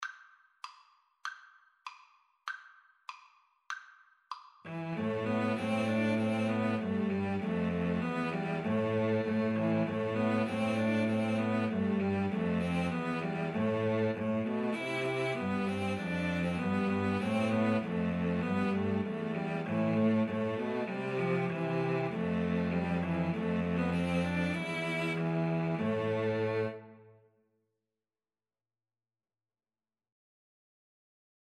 Christmas Christmas Cello Trio Sheet Music Huron Carol
Free Sheet music for Cello Trio
A minor (Sounding Pitch) (View more A minor Music for Cello Trio )
2/4 (View more 2/4 Music)
Cello Trio  (View more Easy Cello Trio Music)
Traditional (View more Traditional Cello Trio Music)